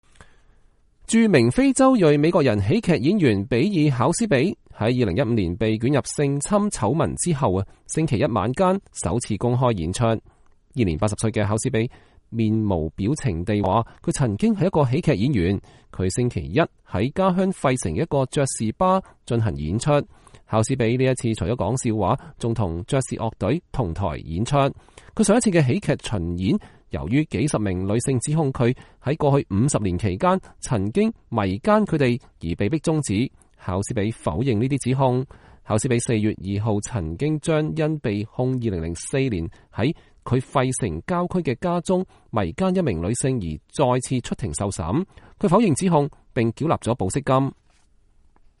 他週一在家鄉費城的一個爵士吧進行演出。
考斯比這次除了說笑話，還與爵士隊同台演出。